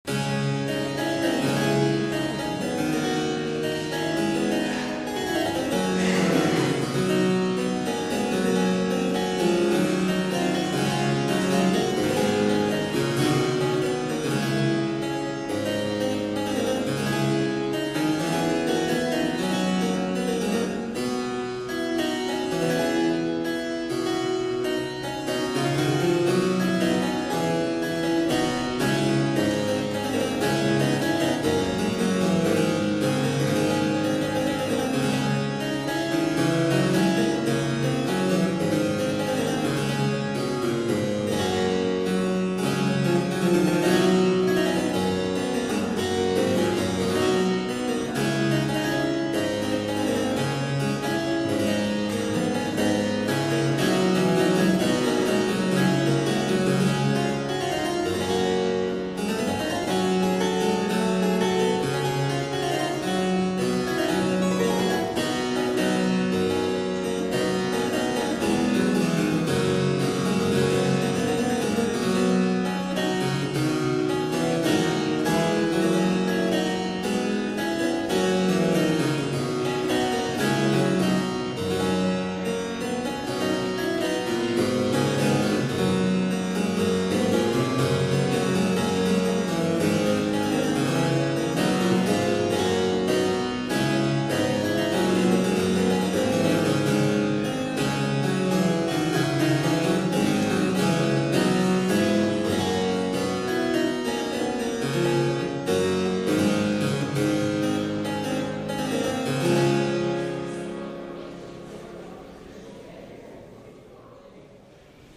Clavicembalo da Giovanni Battista Giusti